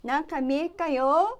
Aizu Dialect Database
Type: Yes/no question
Final intonation: Rising
Location: Aizuwakamatsu/会津若松市
Sex: Female